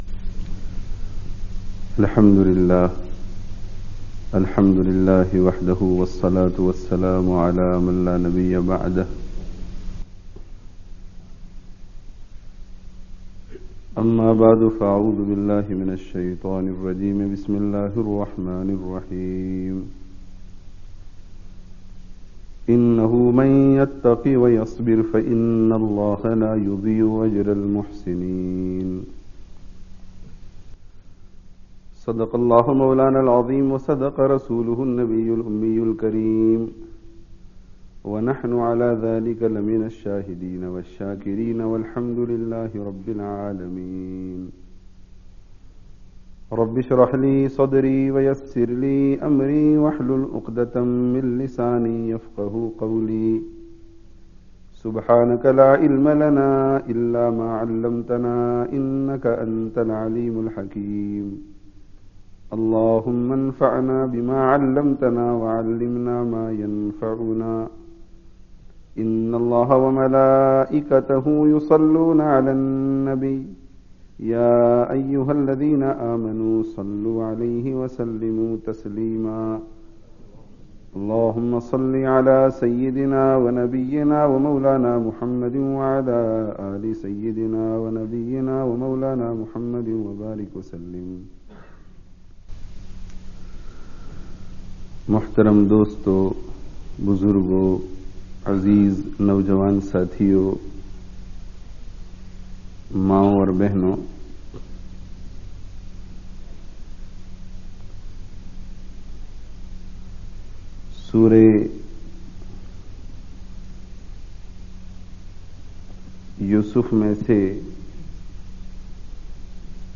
Yūsuf 'alayhis salām Kī Sīrat se Kuch Durūs [Parts 1&2] (Masjid An Noor, Leicester 01/04/05 & 08/04/05)